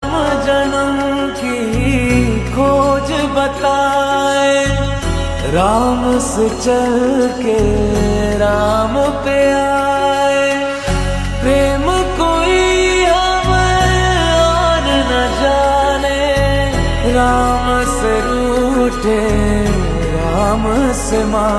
Epic Melody from an Indian Mythological Masterpiece